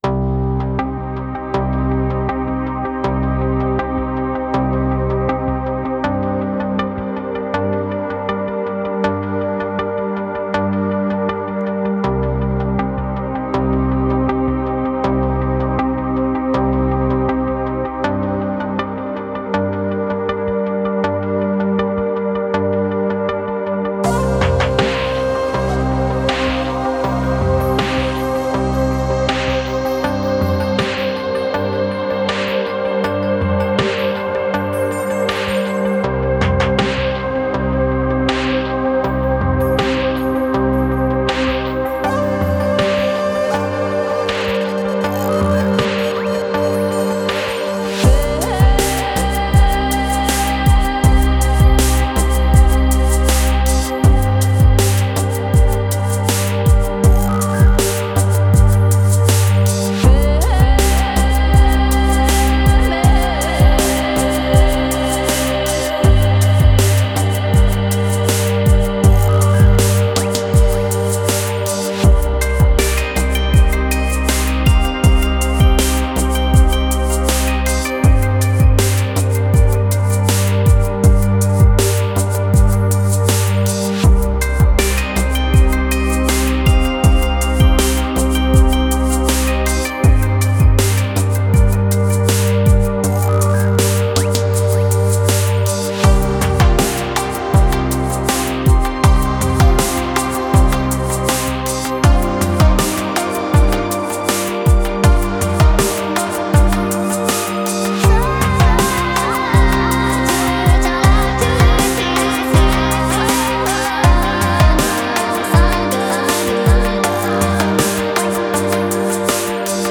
Downtempo, Lounge, Chill Out